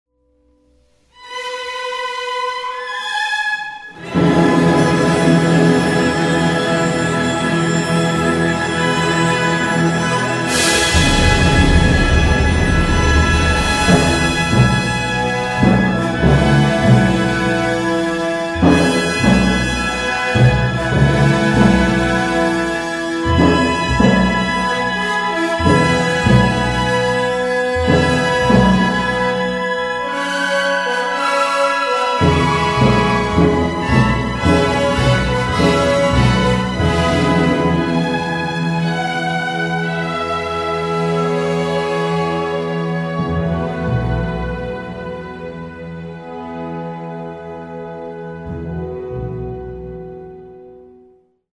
LugarPalacio de la Música
La OSY abre su temporada con la Sinfonía No. 4 del compositor austriaco Gustav Mahler escrita para soprano y orquesta, creación excepcional con respecto al resto de las obras del compositor, ya que no requiere trombones y tuba, de tal forma que el contingente de instrumentos de metal es del tamaño habitual de una sinfónica del siglo XIX (con cuatro trompas o cornos y tres trompetas).